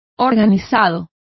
Complete with pronunciation of the translation of organized.